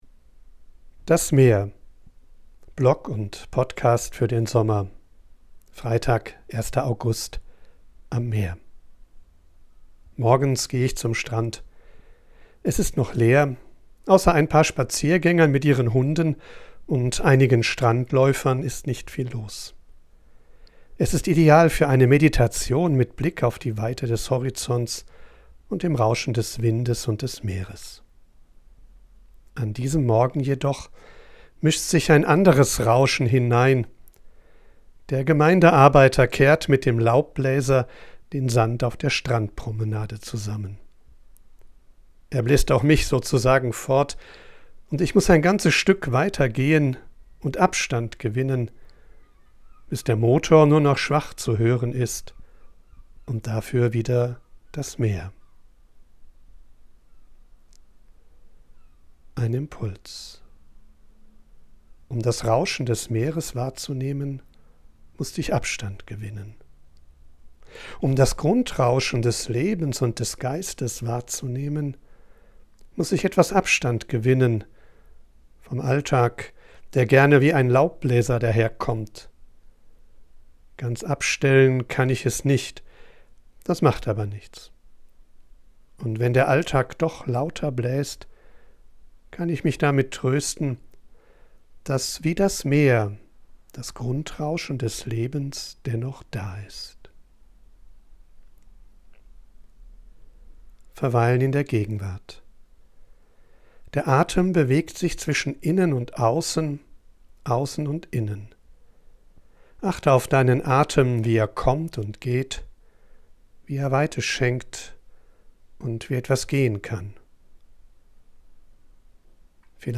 Ich bin am Meer und sammle Eindrücke und Ideen.
von unterwegs aufnehme, ist die Audioqualität begrenzt.
mischt sie mitunter eine echte Möwe und Meeresrauschen in die